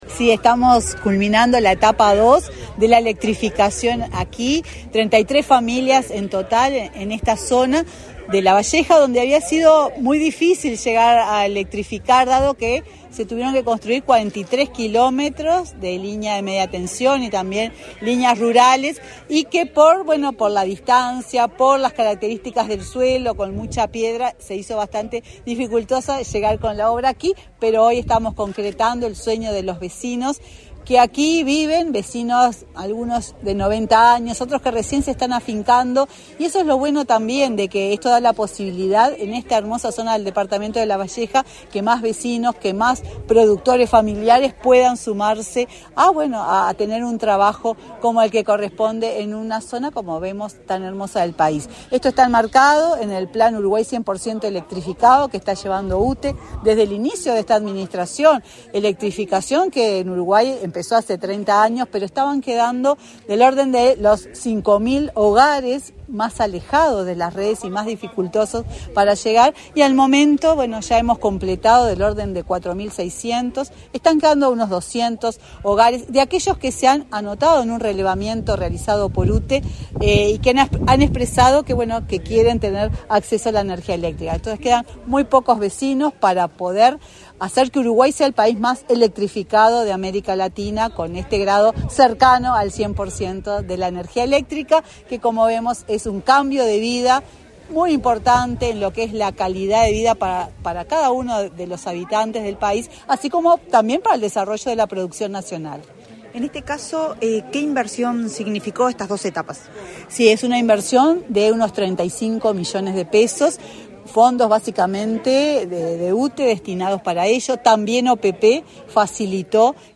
Entrevista de la presidenta de UTE, Silvia Emaldi
Entrevista de la presidenta de UTE, Silvia Emaldi 20/12/2024 Compartir Facebook X Copiar enlace WhatsApp LinkedIn Tras la inauguración de obras de electrificación rural en el departamento de la Lavalleja, este 20 de diciembre, la presidenta de la UTE, Silvia Emaldi, dialogó con Comunicación Presidencial.